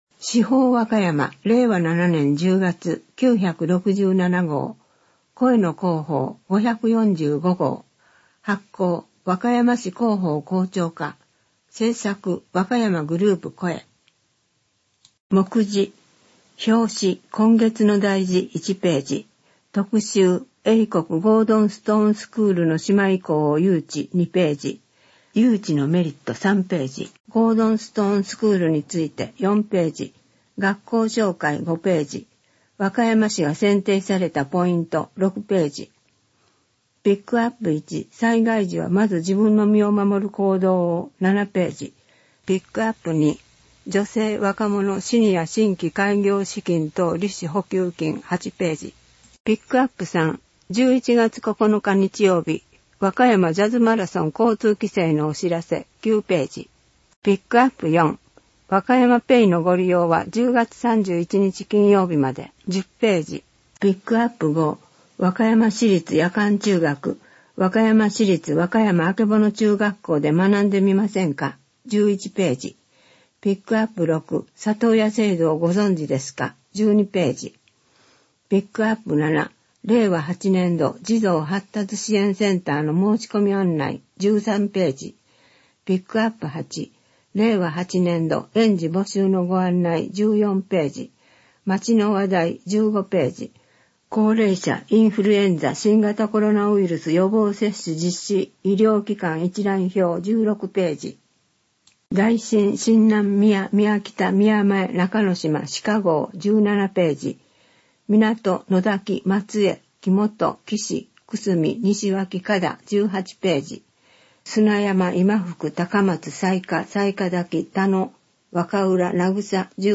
市報わかやま 令和7年10月号（声の市報）